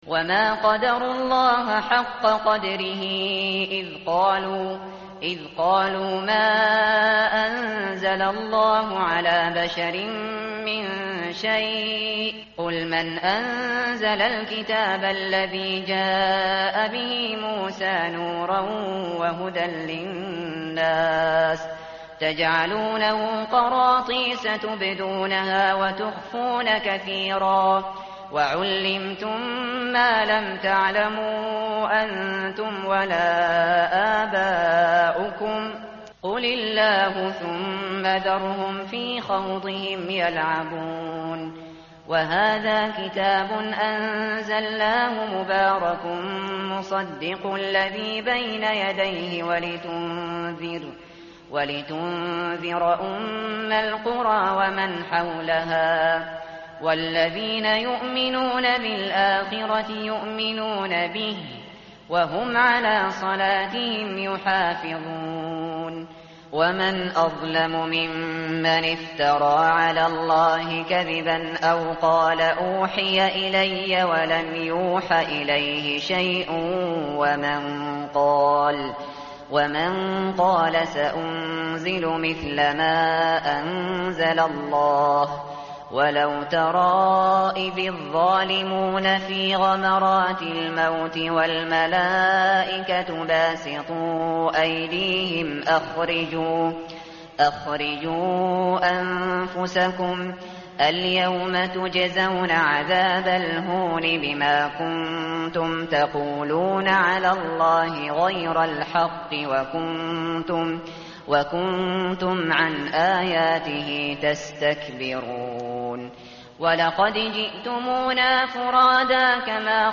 tartil_shateri_page_139.mp3